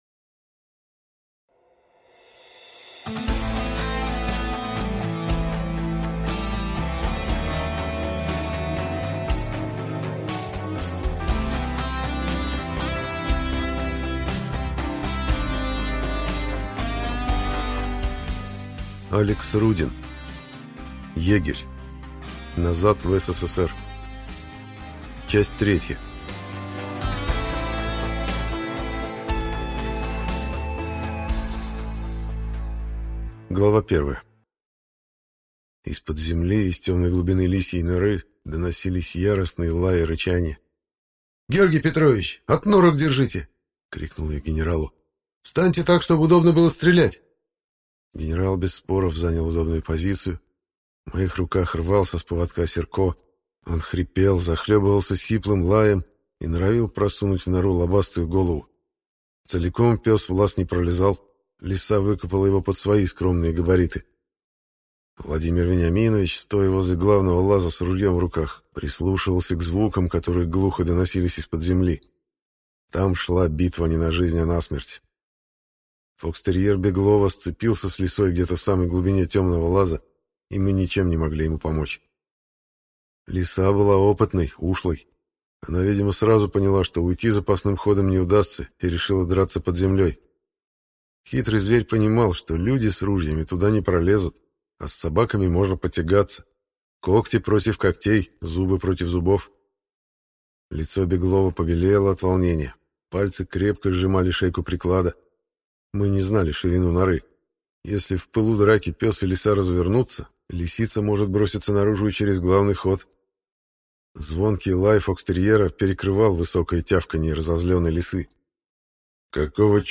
Аудиокнига Егерь – 3: Назад в СССР | Библиотека аудиокниг